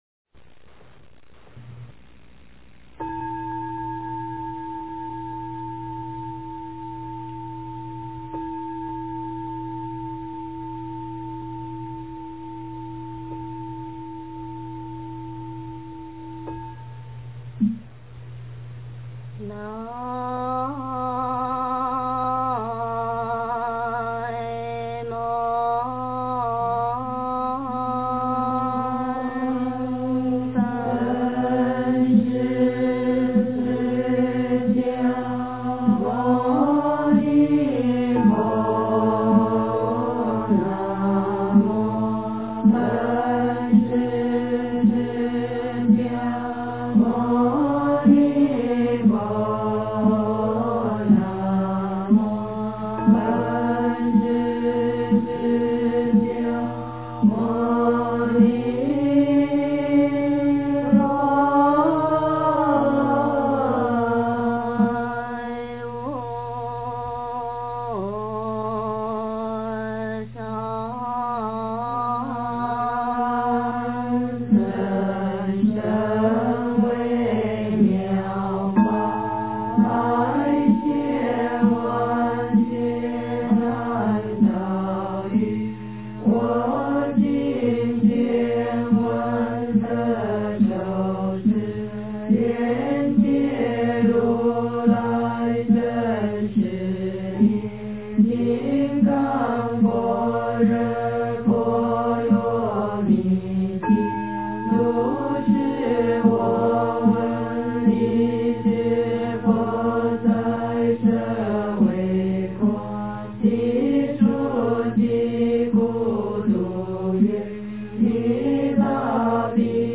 诵经
佛音 诵经 佛教音乐 返回列表 上一篇： 大般若波罗蜜多经第469卷 下一篇： 阿难问事佛吉凶经 相关文章 人生何处不是空--佛教音乐 人生何处不是空--佛教音乐...